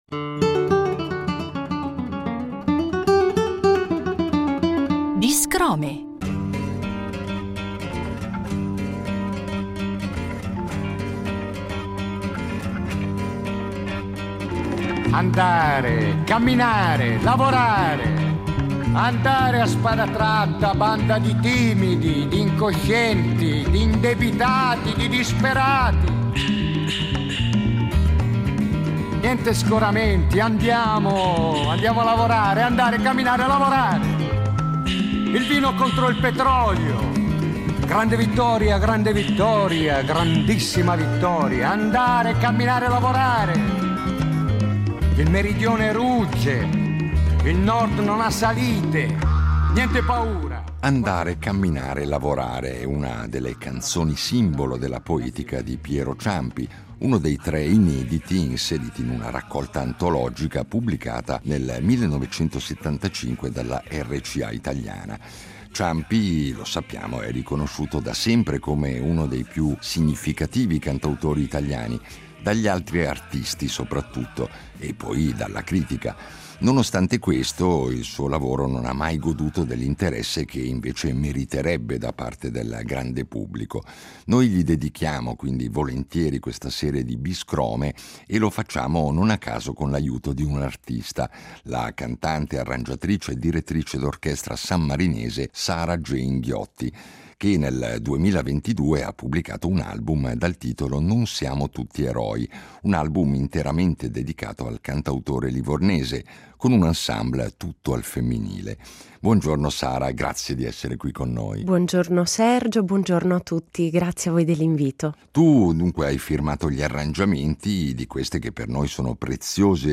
Ogni puntata di Non siamo tutti eroi segue una precisa drammaturgia radiofonica. A ciascun episodio è dedicato un brano, punto di partenza per riflessioni musicali, linguistiche e persino antropologiche. L’apertura propone un frammento originale delle storiche incisioni di Piero Ciampi, come gesto di memoria e invocazione d’archivio.
In chiusura, l’ascolto integrale della rilettura musicale tratta dal disco Non siamo tutti eroi, interpretata da un ensemble interamente femminile.